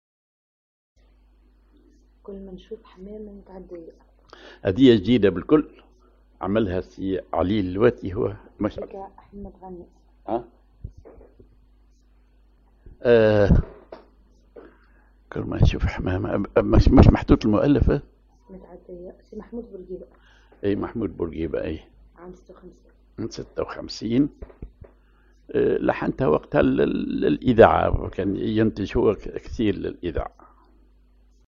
Maqam ar حجاز
Rhythm ar حلة أو غيطة
genre أغنية